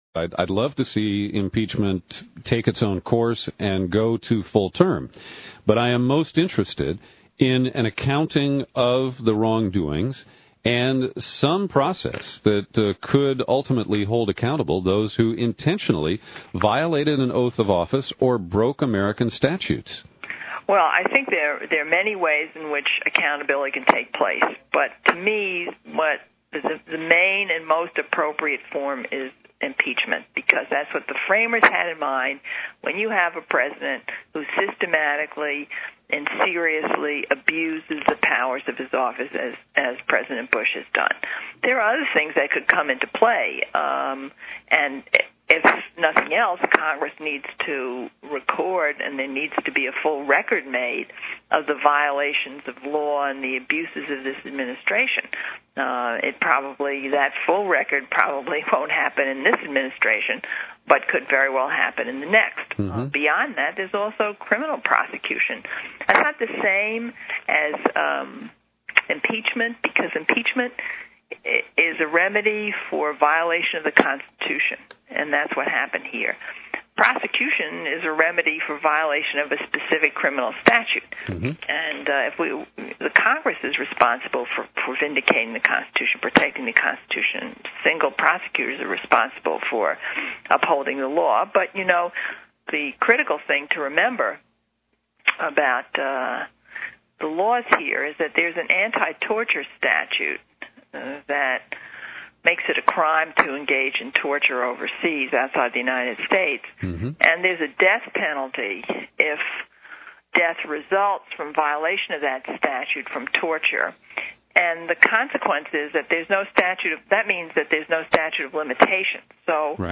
Here is a four-minute QuickTime excerpt from the interview: